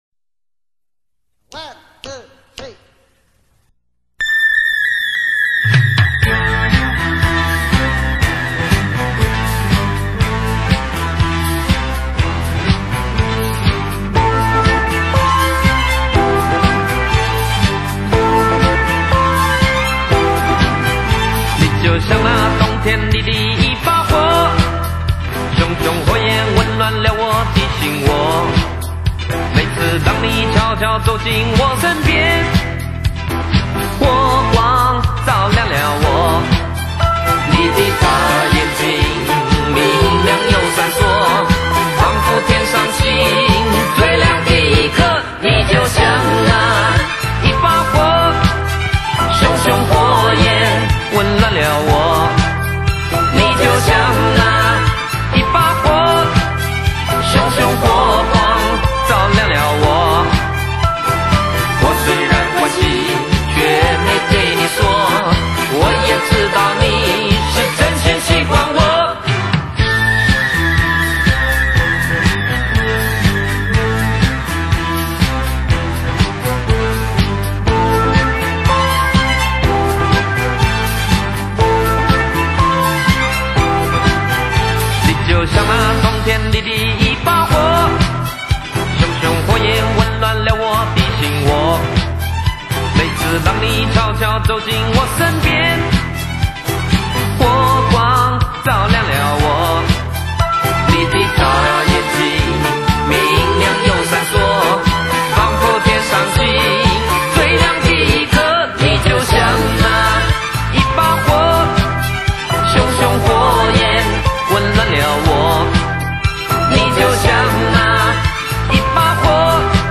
比较火爆